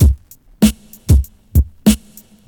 • 97 Bpm 2000s Drum Beat C# Key.wav
Free drum beat - kick tuned to the C# note. Loudest frequency: 1117Hz